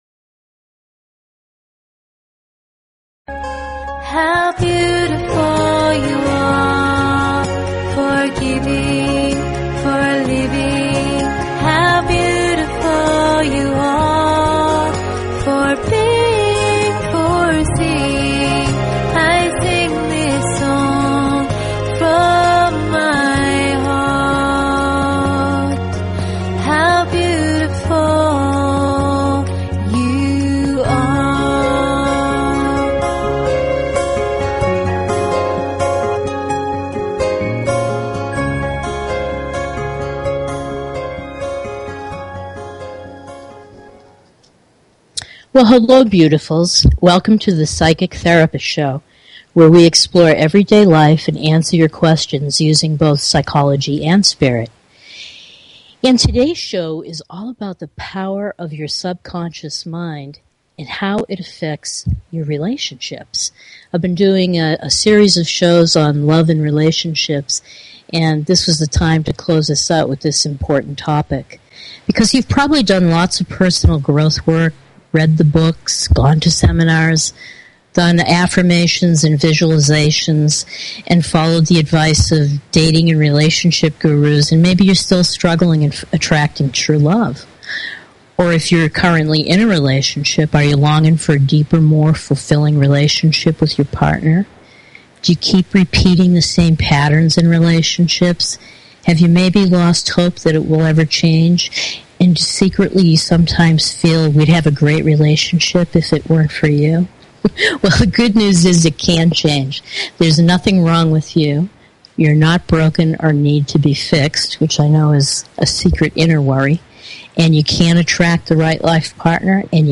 Talk Show Episode, Audio Podcast, Psychic_Therapist_Show and Courtesy of BBS Radio on , show guests , about , categorized as